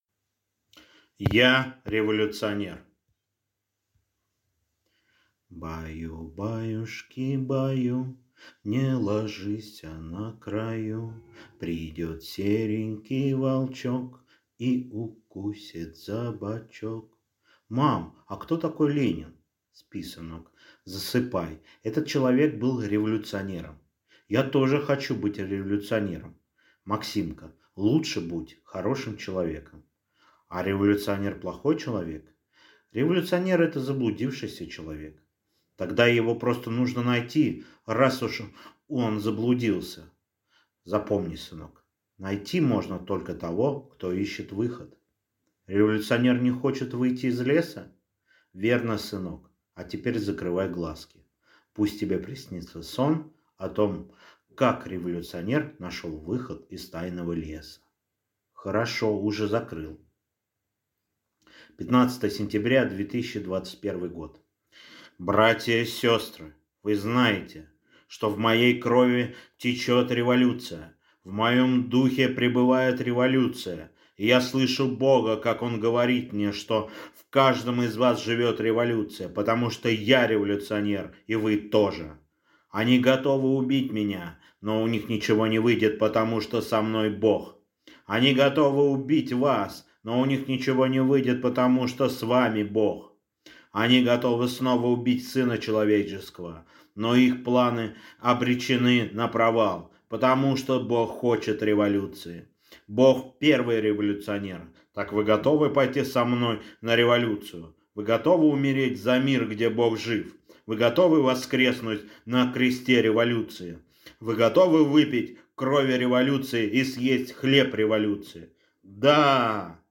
Аудиокнига Я – Революционер | Библиотека аудиокниг